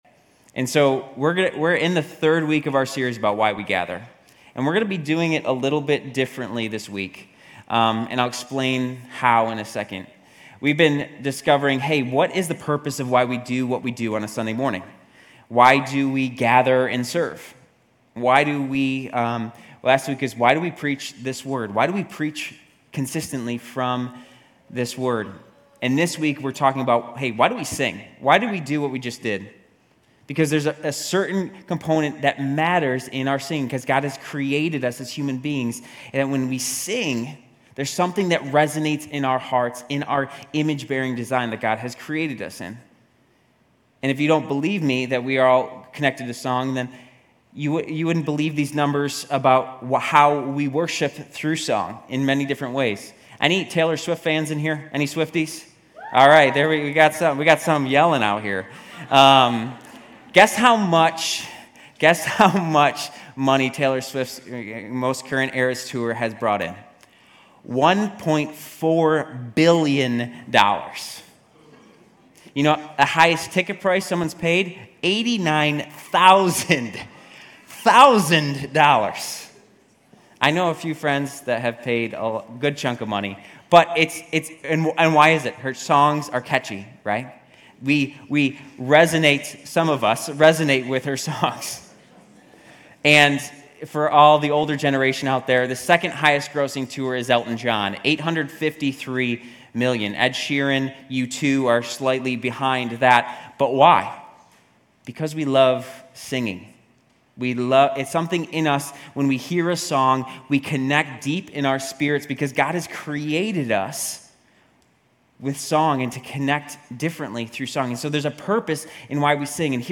GCC-UB-September-10-Sermon.mp3